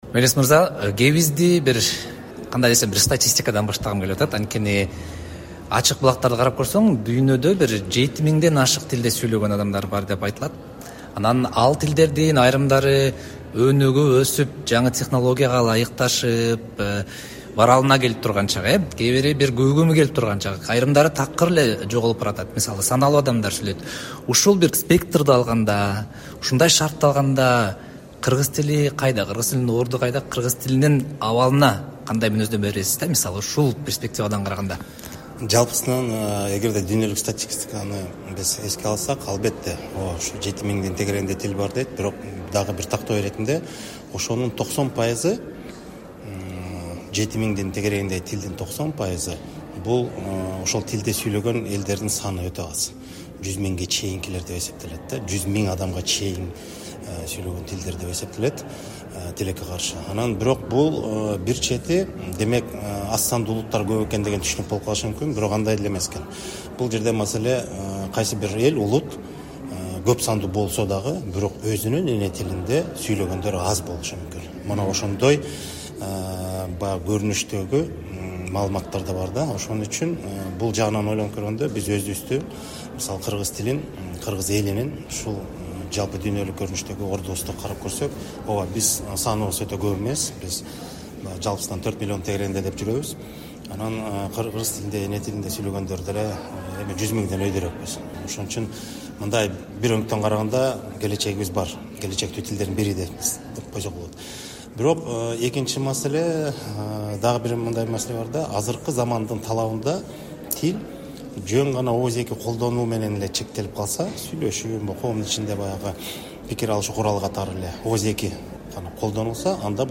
23-сентябрда кыргыз тили мамлекеттик макамга ээ болгонуна 35 жыл толду. Аны утурлай кыргыз тилинин абалы, аны өнүктүрүүгө тоскоол болгон жагдайлар тууралуу президентке караштуу Мамлекеттик тил жана тил саясаты боюнча улуттук комиссиясынын төрагасы Мелис Мураталиев “Азаттыкка” ат жалында маек курду.